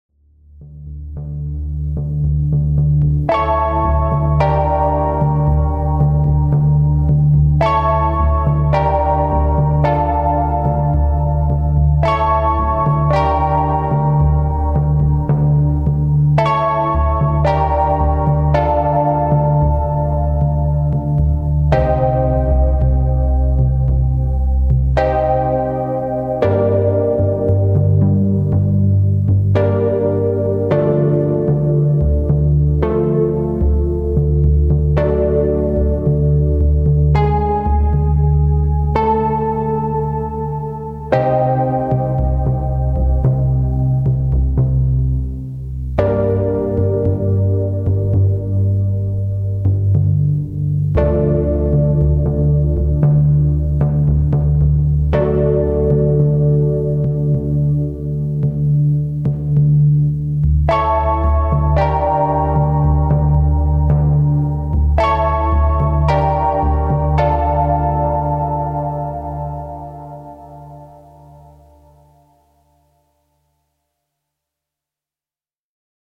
This is really rough and I’m just playing it live, no sequencing (which will be obvs :grinning:), but this is a sound I’ve just been working on from samples imported from my A4 mk2… so the PX lets me play it poly too.